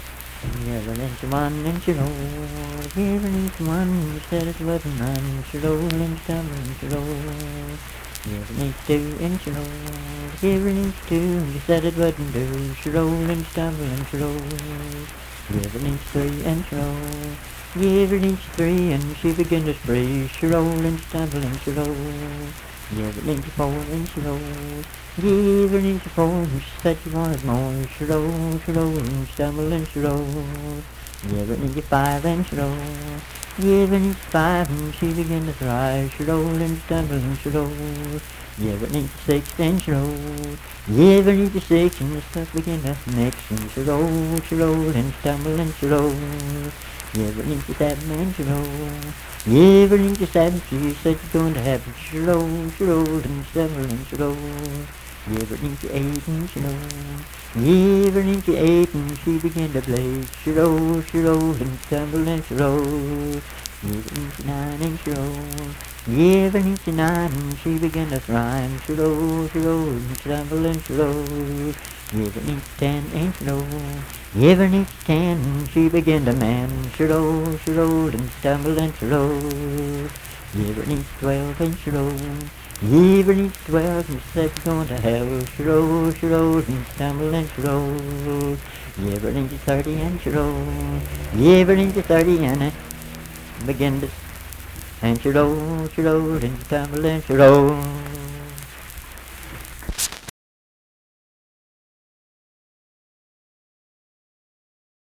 Unaccompanied vocal music
Performed in Dundon, Clay County, WV.
Bawdy Songs
Voice (sung)